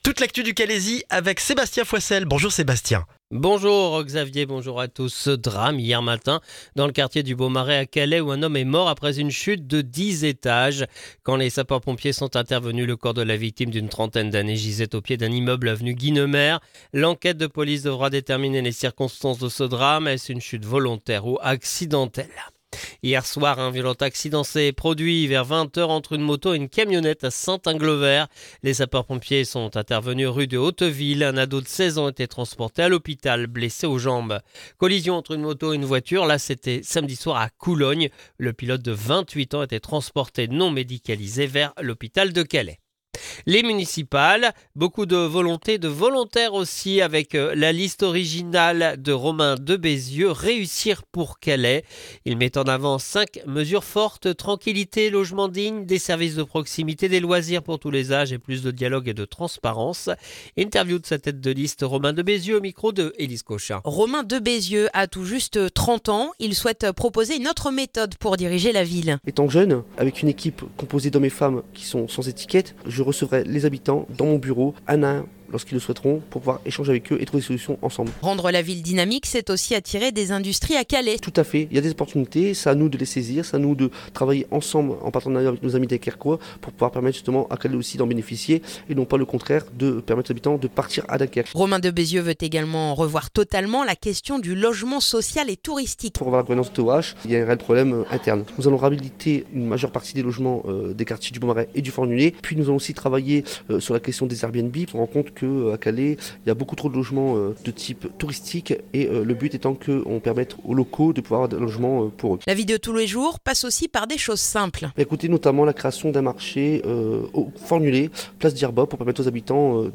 Le journal du lundi 9 mars dans le calaisis